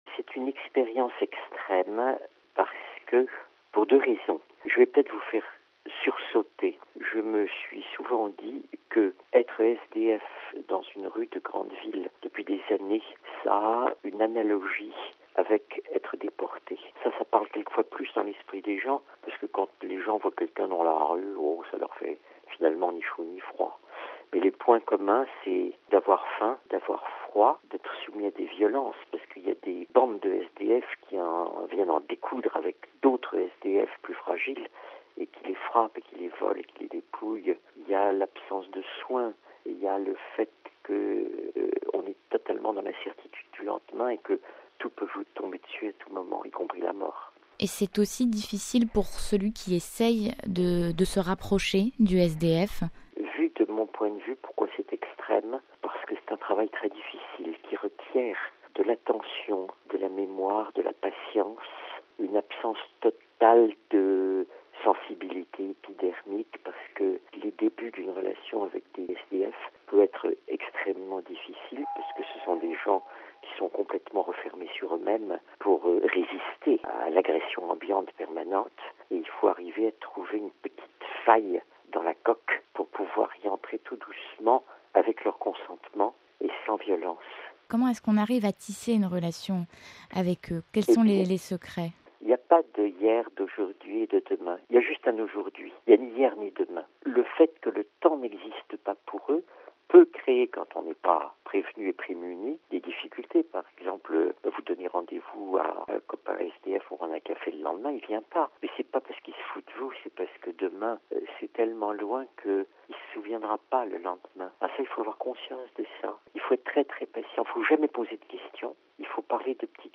Témoignage.